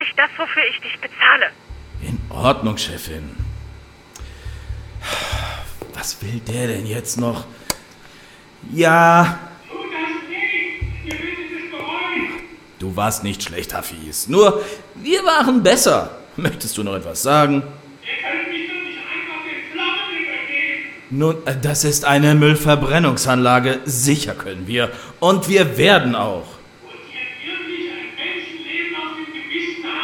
Genre : Hörspiel